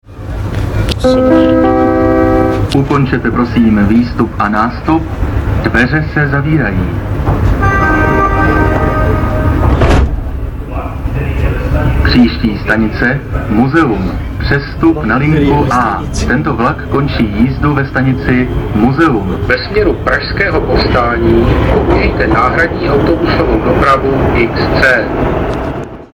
- Vlakový rozhlas: